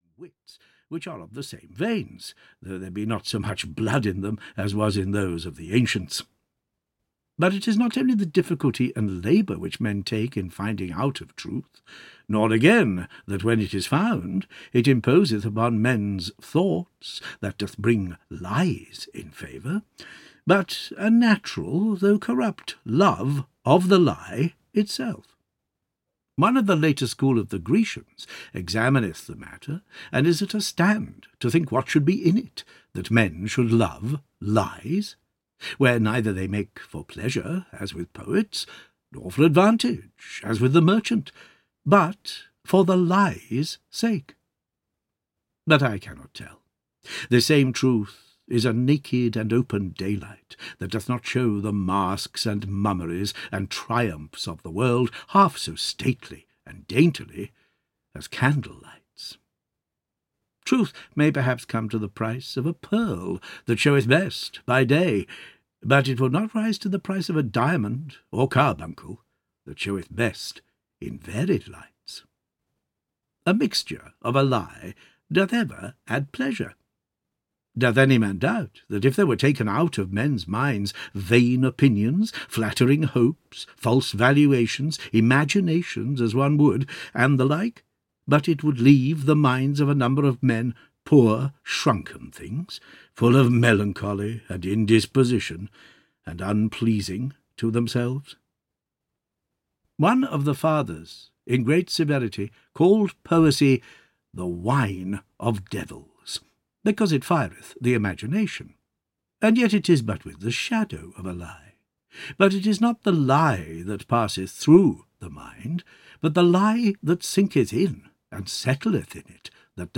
Essays (EN) audiokniha
Ukázka z knihy